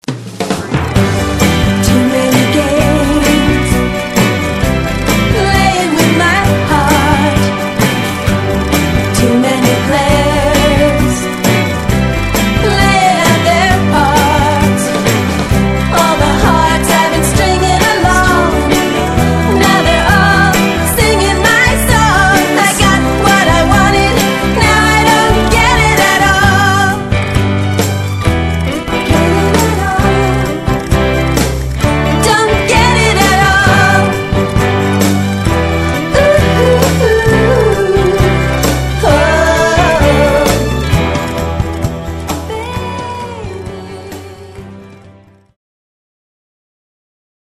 VOCAL DEMO
film track